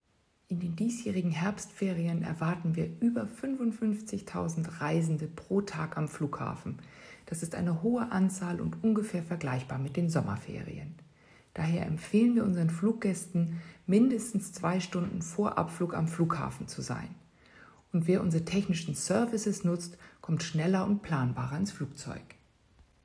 Audio-Statements